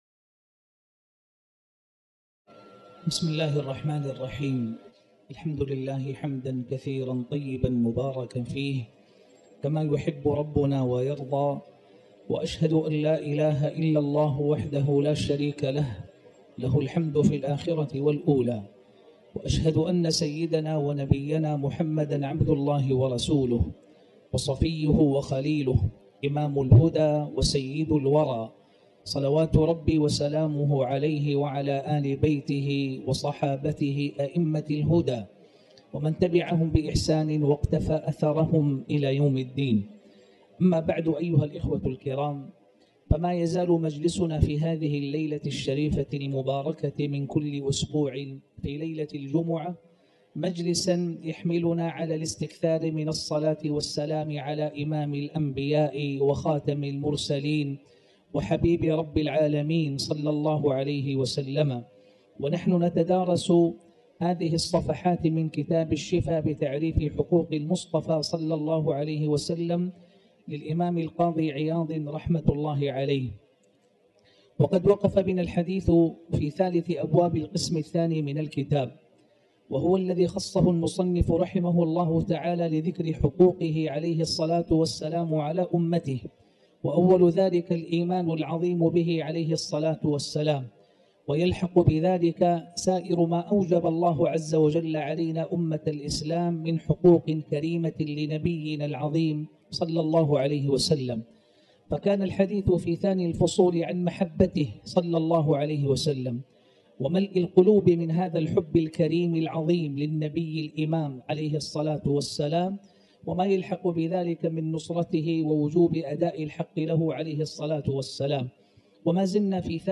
تاريخ النشر ٢٥ جمادى الأولى ١٤٤٠ هـ المكان: المسجد الحرام الشيخ